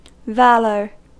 Ääntäminen
Ääntäminen US
IPA : /ˈvæl.ə(ɹ)/